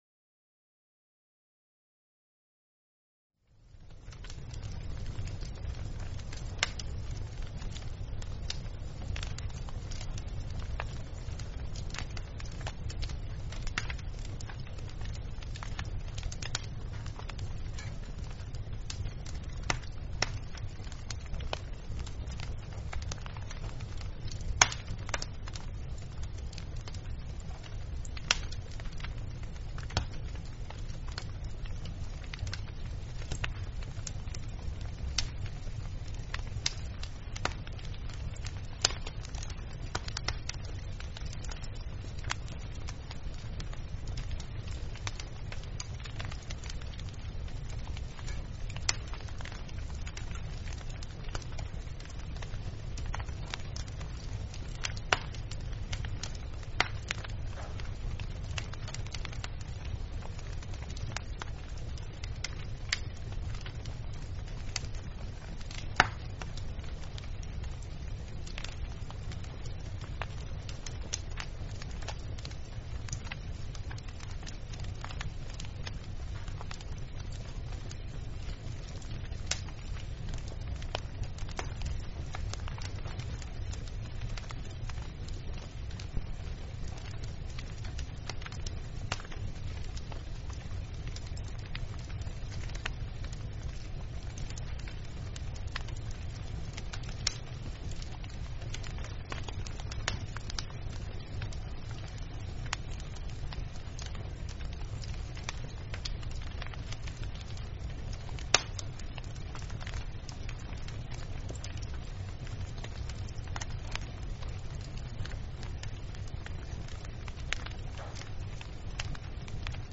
2hour+ Non-looping Fire Crackling Sound MP3 V3 - For Trebisky Fire Crackler Speaker
Experience the soothing ambiance of a crackling fire with our 2-hour+ non-looping Fire Crackler sound MP3 V3, perfect for the Trebisky Fire Crackler Speaker.